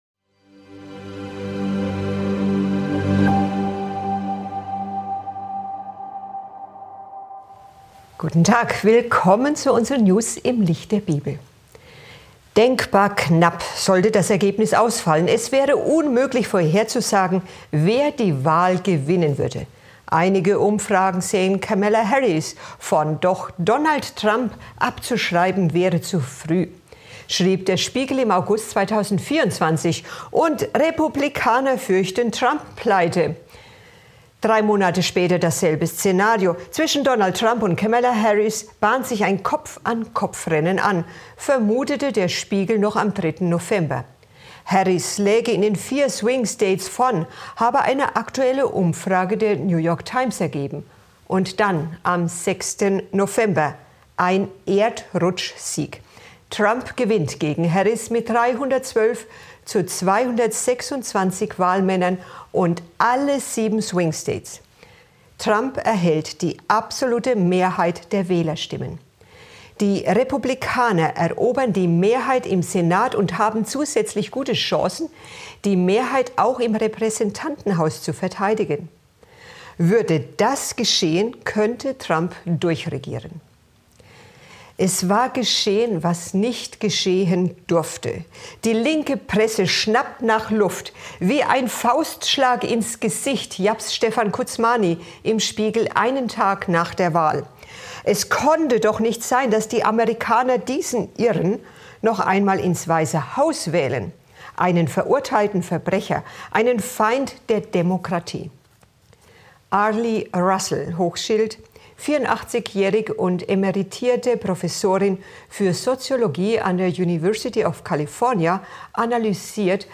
In diesem packenden Vortrag wird die aufgeladene politische Lage in den USA analysiert. Die Rolle der katholischen Kirche und deren Einfluss auf die amerikanische Politik werden ebenso thematisiert wie die gesellschaftlichen Spannungen.